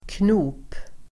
Uttal: [kno:p]